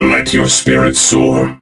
robo_bo_kill_02.ogg